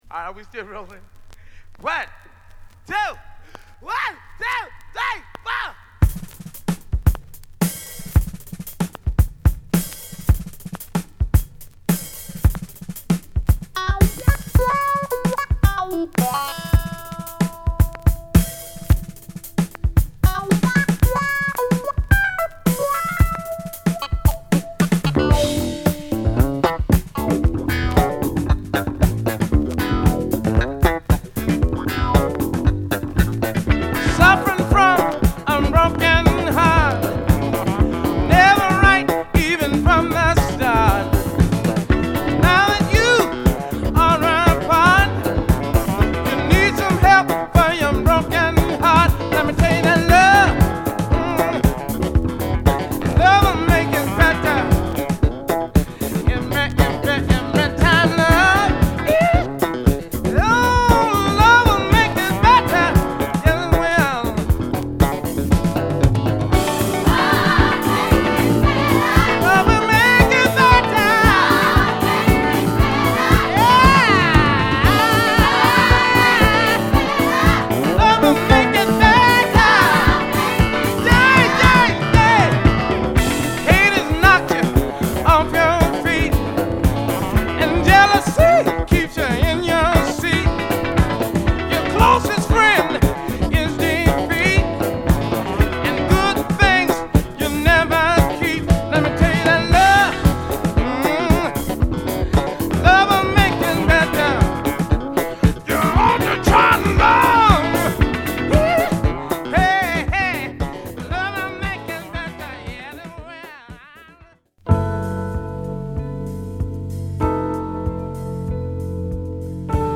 ＊A1頭にプチノイズ。